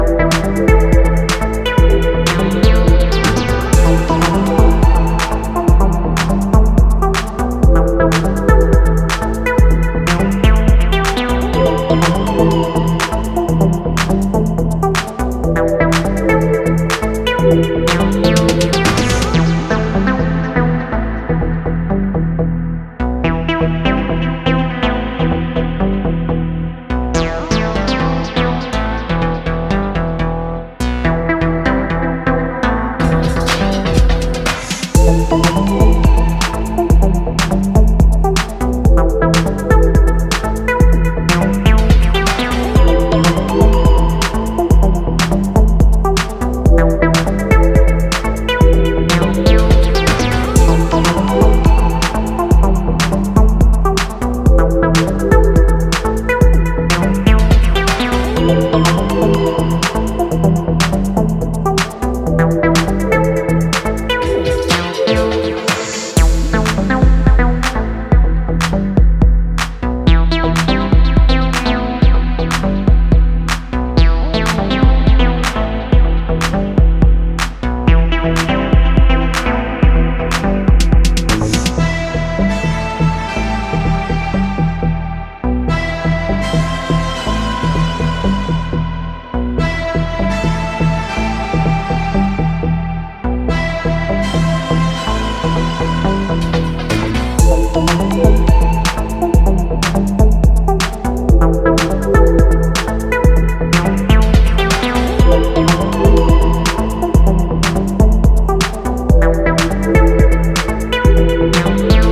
electronic music producer